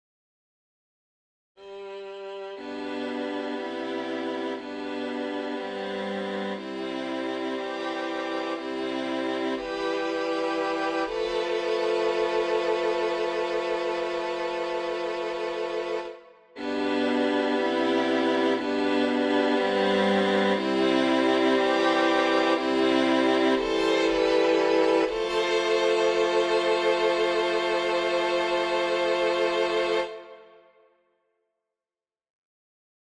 Key written in: C Major
How many parts: 4
Type: SATB
All Parts mix: